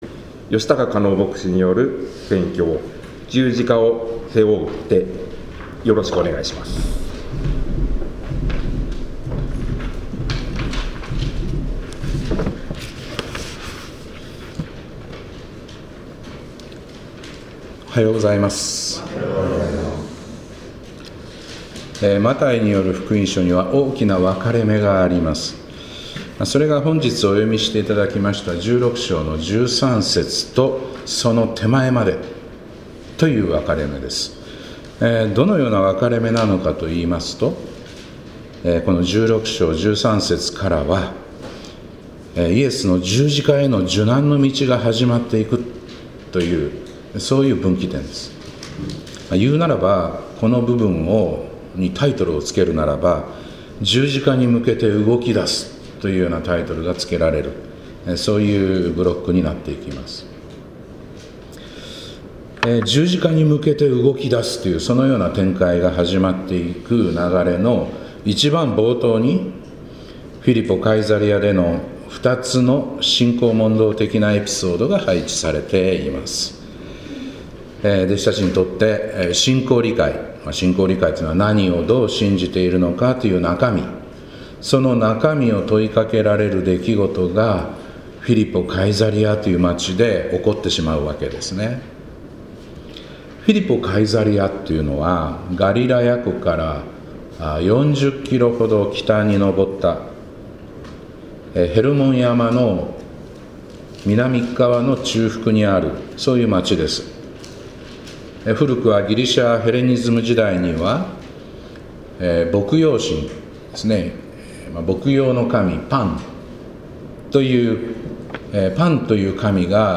2025年2月23日礼拝「”十字架を背負う”って」